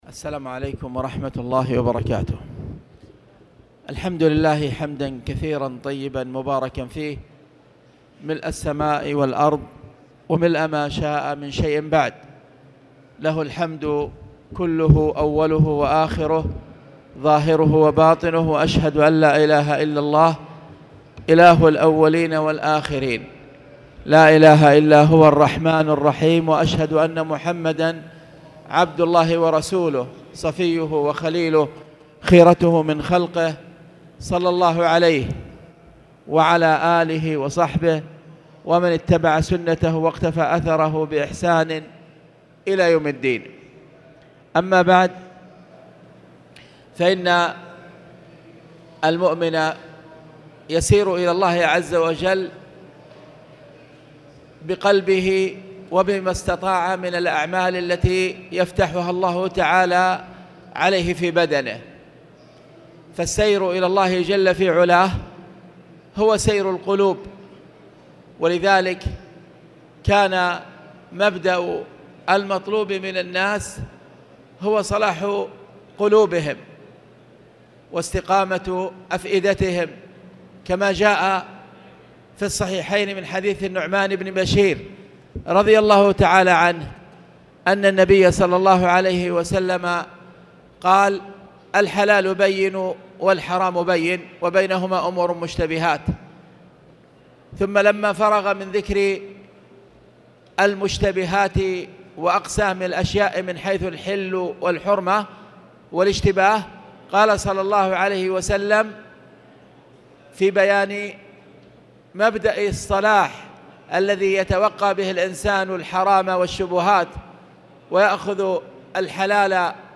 تاريخ النشر ٣ شعبان ١٤٣٨ هـ المكان: المسجد الحرام الشيخ: خالد بن عبدالله المصلح خالد بن عبدالله المصلح توحيد الله